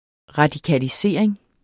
Udtale [ ʁɑdikaliˈseˀɐ̯eŋ ]